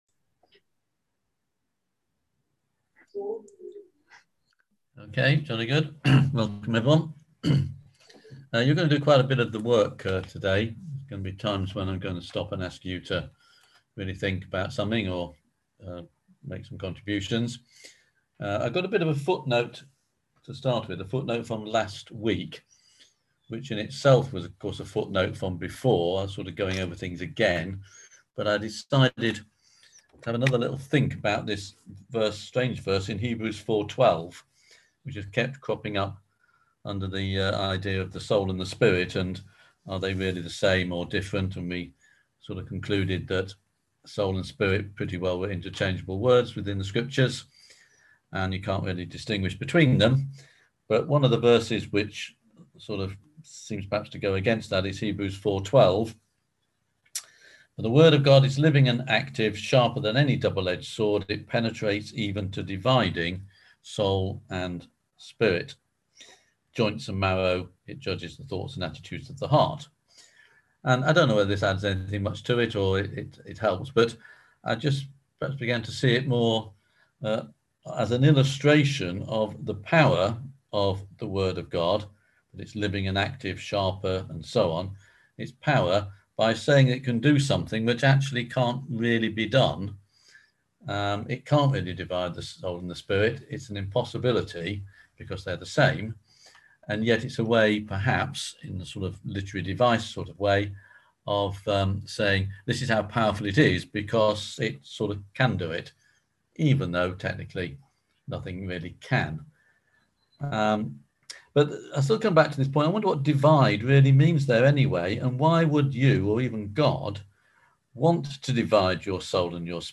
On September 16th at 7pm – 8:30pm on ZOOM